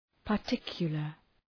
Προφορά
{pər’tıkjələr}